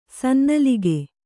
♪ sannalige